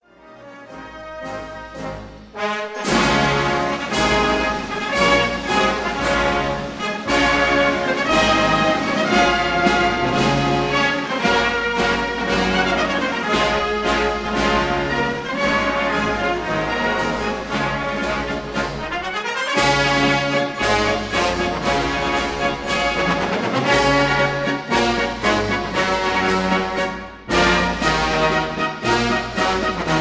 recorded in summer 1955